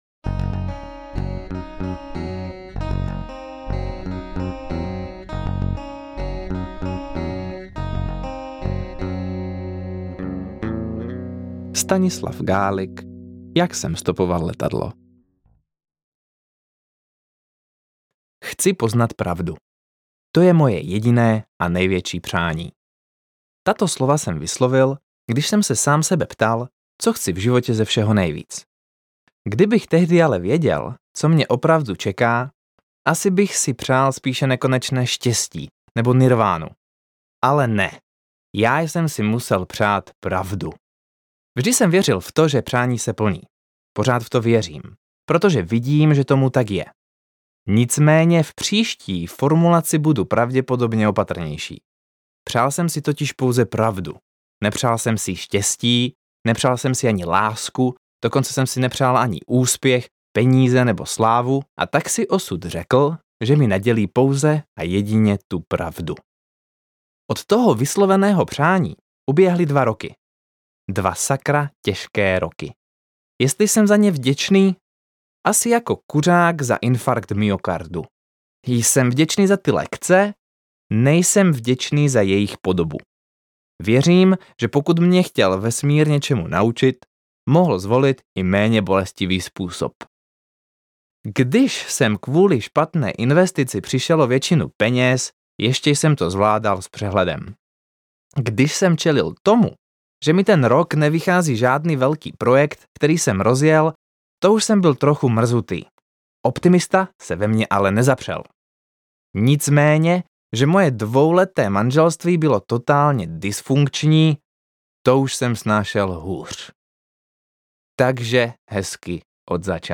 AudioKniha ke stažení, 88 x mp3, délka 13 hod. 42 min., velikost 1129,0 MB, česky